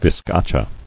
[viz·ca·cha]